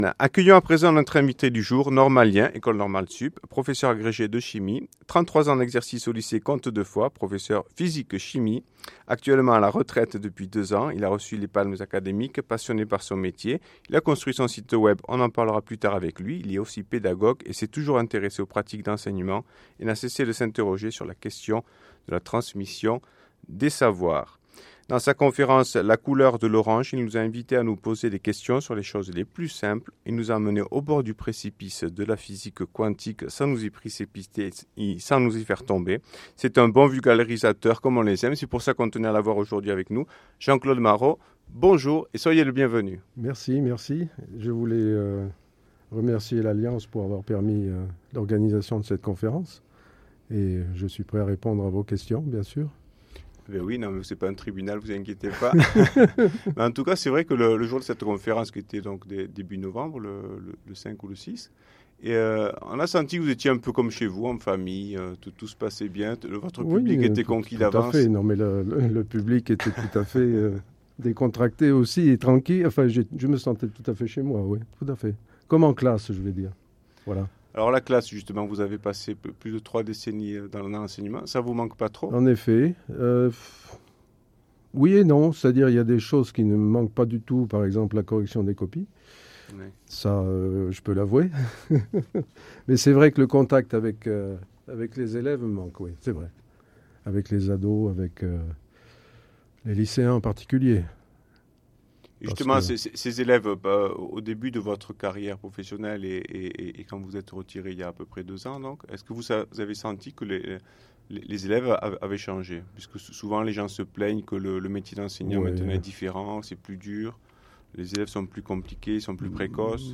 interview-af-rna.mp3